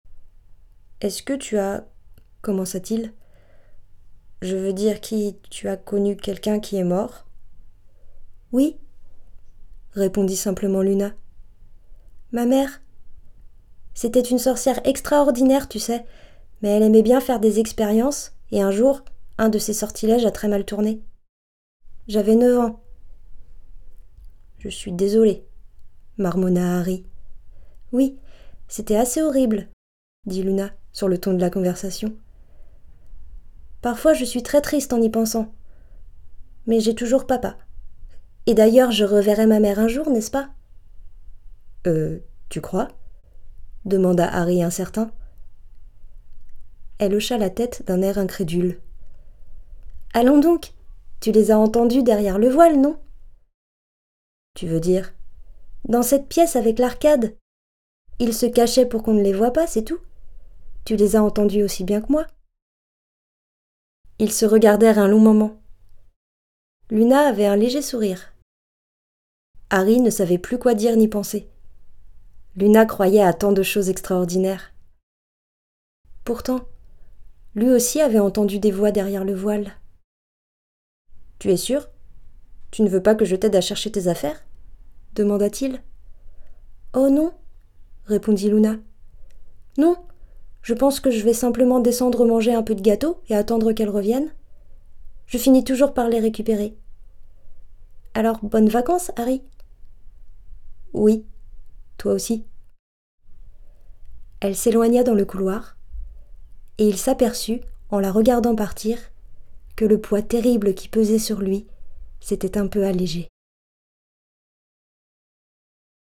Narration Harry Potter - JK Rowling
14 - 34 ans - Soprano